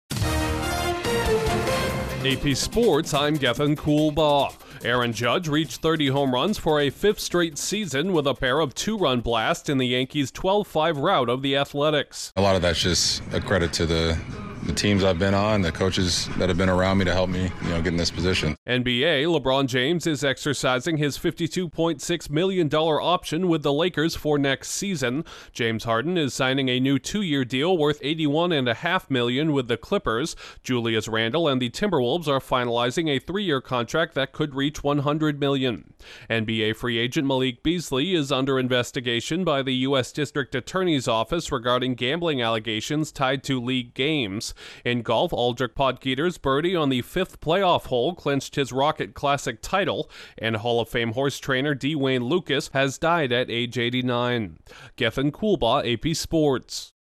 Aaron Judge notches another 30-homer season, LeBron James opts in for an unprecedented 23rd season, two more NBA All-Stars agree to new contracts, a former first-round pick in basketball is investigated for gambling, a playoff finish at the Rocket Classic and a storied horse trainer dies. Correspondent